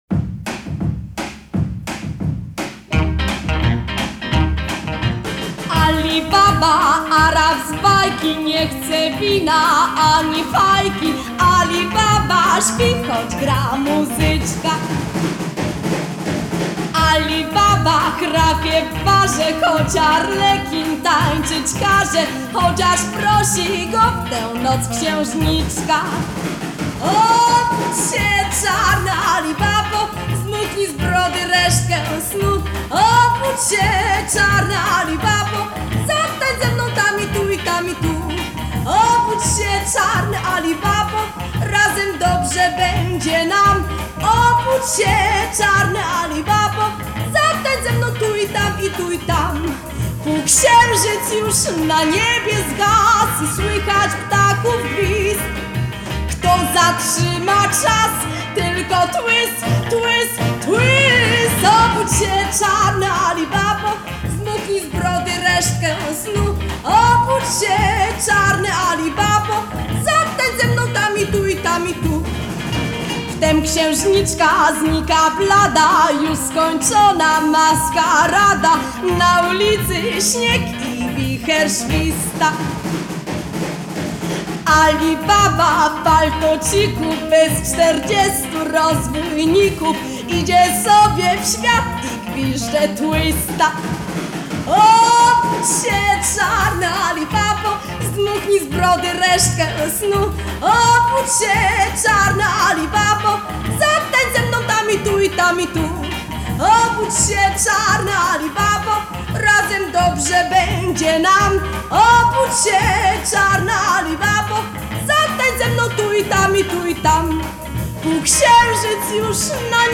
Genre: Retro, Twist, 60s, Female Vocal